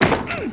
punch.wav